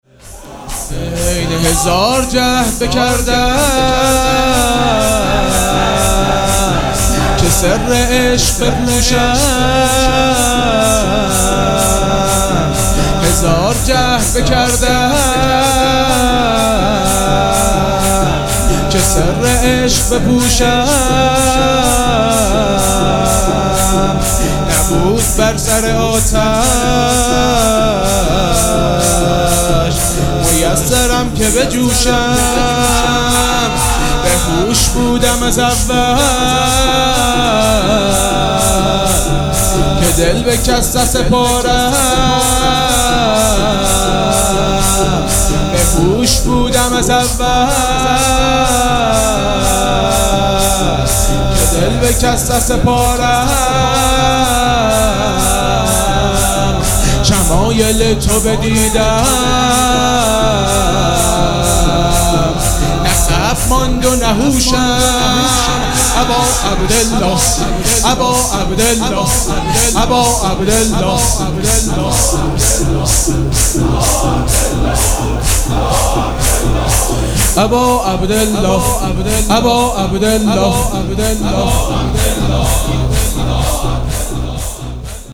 شور
مداح